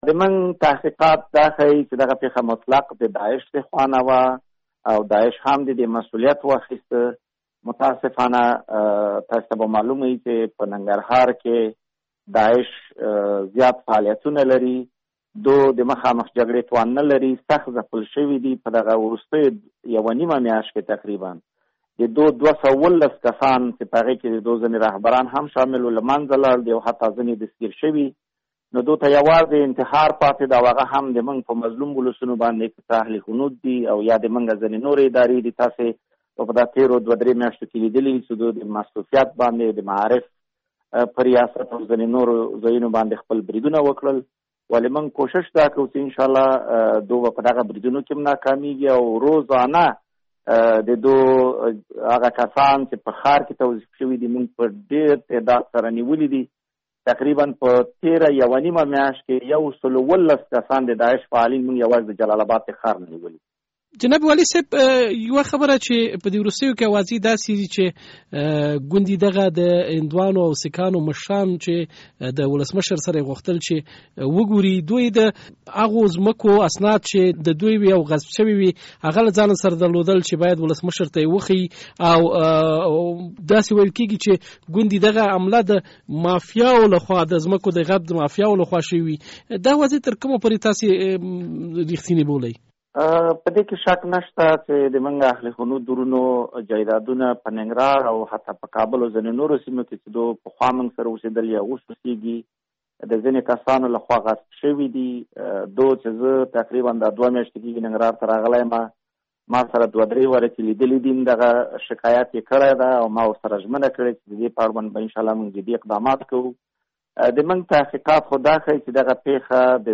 مرکه
له حیات لله حیات سره مرکه